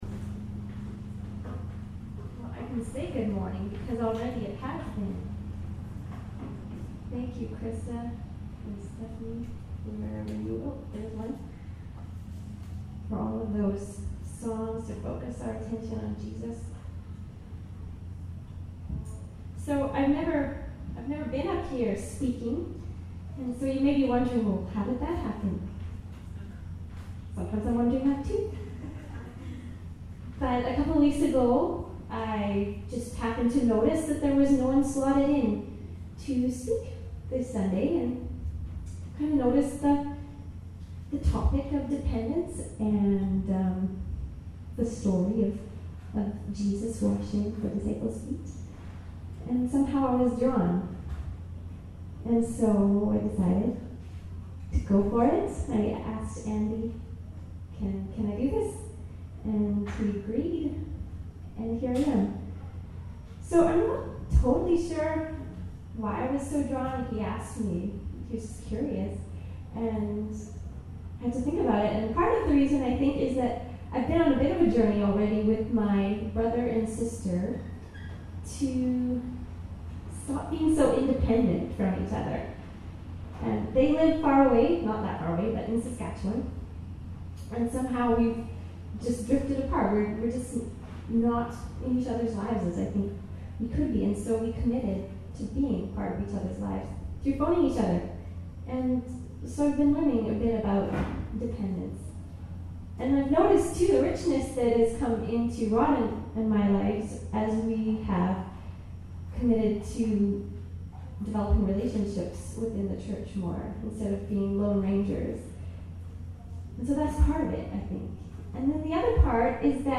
Service Type: Upstairs Gathering